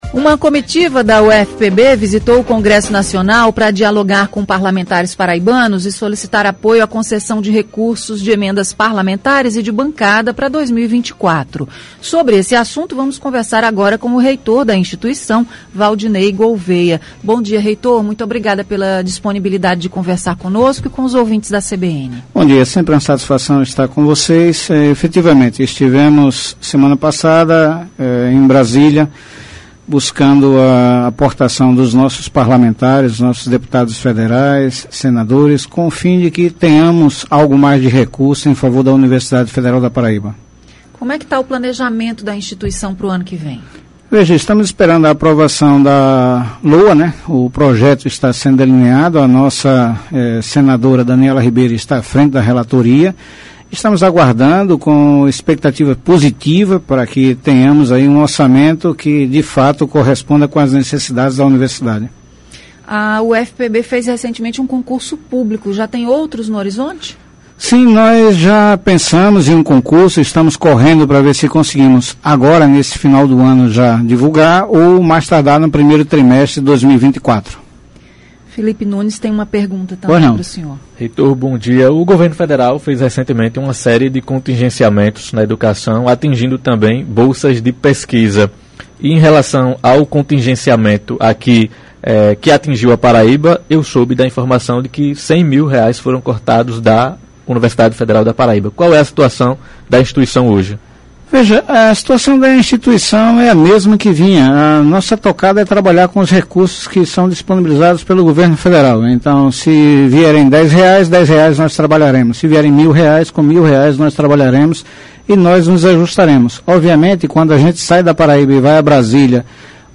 Entrevista: Valdiney Gouveia fala sobre recursos e emendas para a UFPB – CBN Paraíba
Uma comitiva da Universidade Federal da Paraíba (UFPB) visitou o Congresso Nacional para dialogar com parlamentares paraibanos e solicitar apoio à concessão de recursos de emendas parlamentares e de bancada para 2024. Sobre esse assunto, nossa entrevista foi com o reitor da instituição, Valdiney Gouveia.